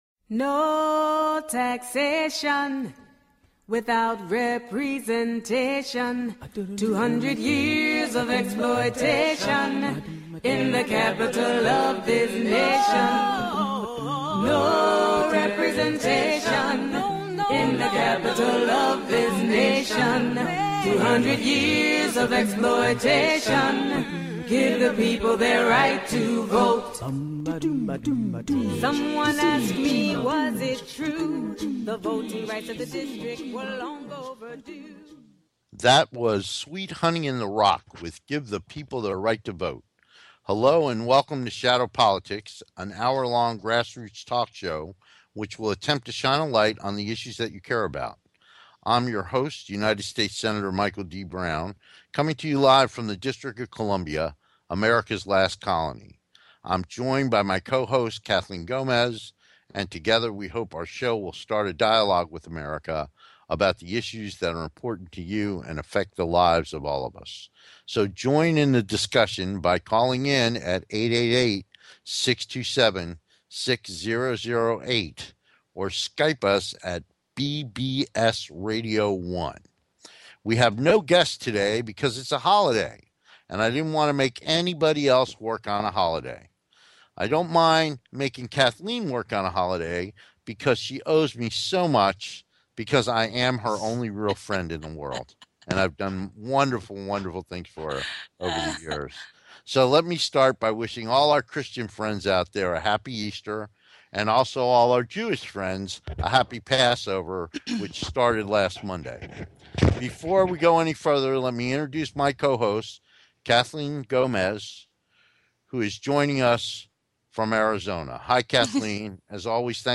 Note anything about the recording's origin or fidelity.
We look forward to having you be part of the discussion so call in and join the conversation.